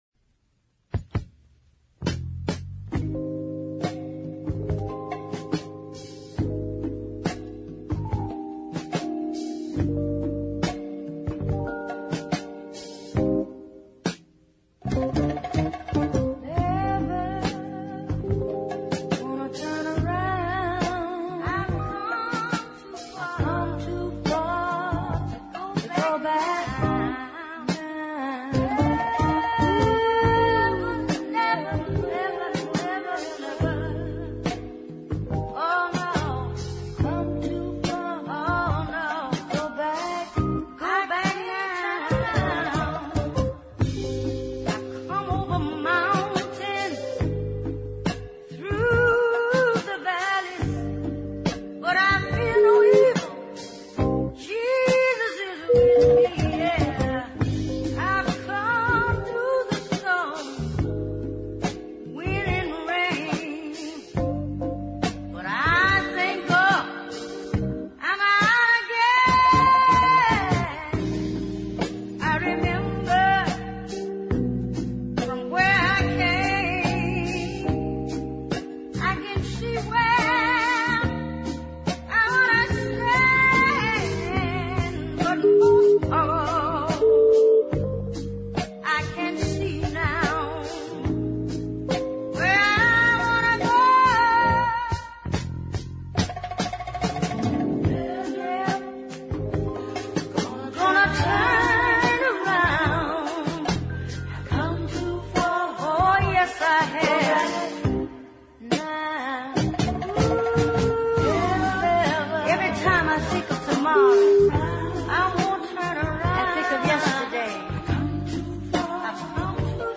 mostly recorded in the early 70s.